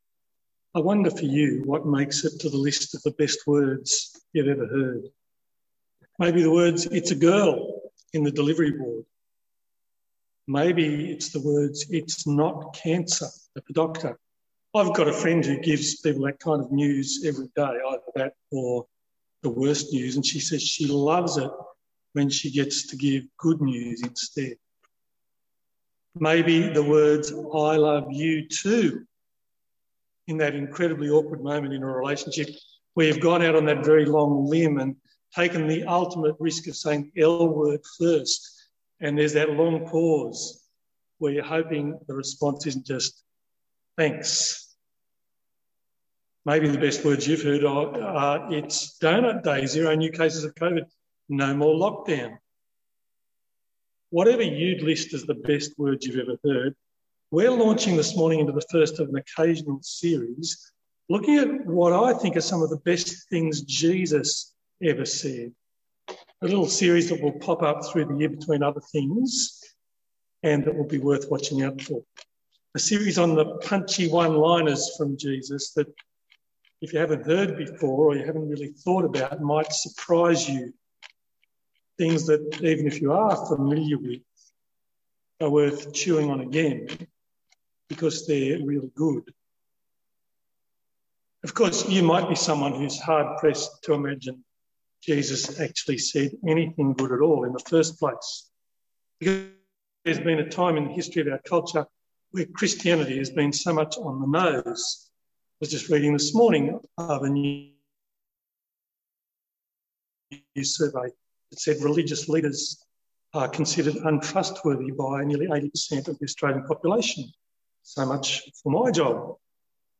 Full Service Audio
The Scots’ Church Melbourne 11am Service 13th of June 2021